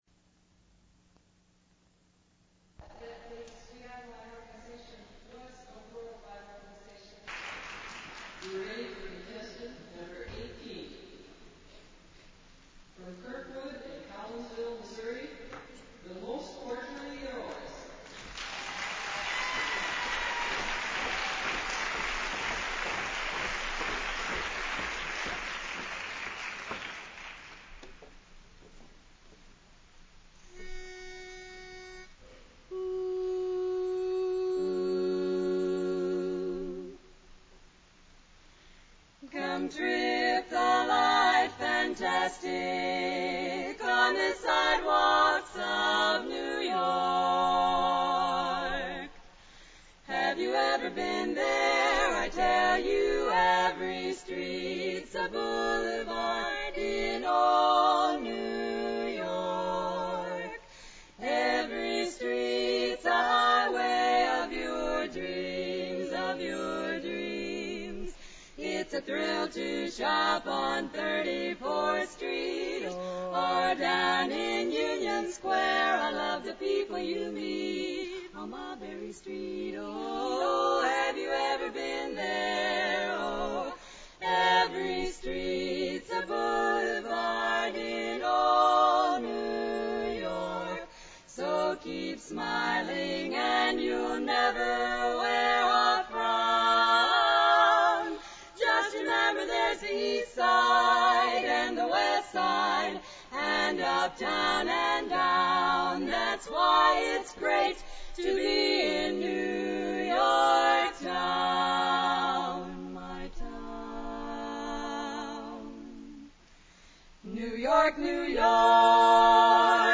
BarbershopQuartet.mp3